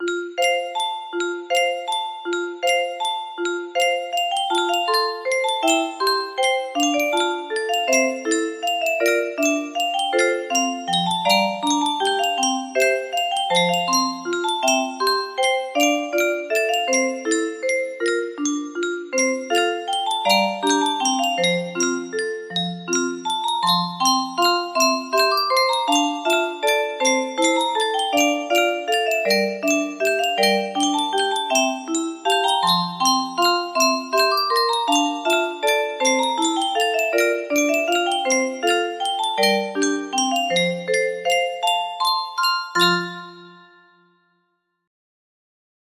Grand Illusions 30 (F scale)
BPM 80